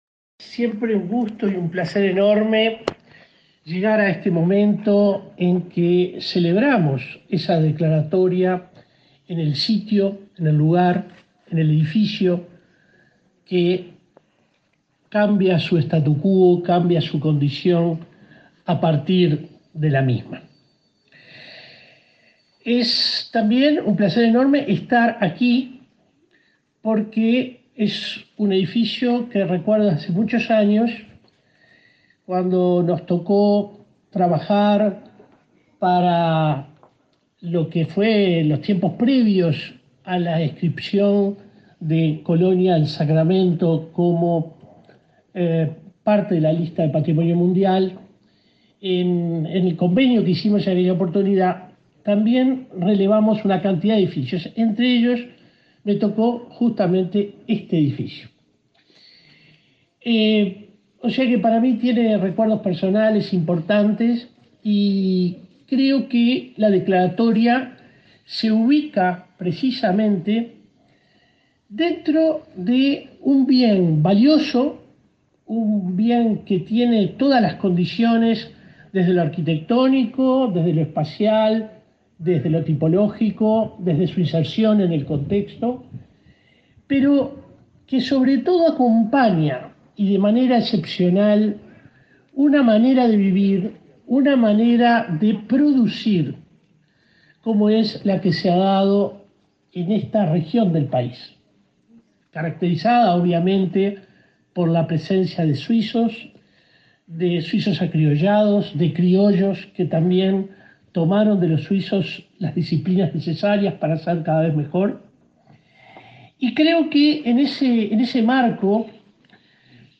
Hotel del Prado de Nueva Helvecia fue declarado Monumento Histórico Nacional 23/02/2022 Compartir Facebook X Copiar enlace WhatsApp LinkedIn El director de la Comisión de Patrimonio, Wiliam Rey; el intendente de Colonia, Carlos Moreira, y el ministro de Educación y Cultura, Pablo da Silveira, participaron este miércoles 23 en el acto en que se declaró Monumento Histórico Nacional al Hotel del Prado de Nueva Helvecia, departamento de Colonia.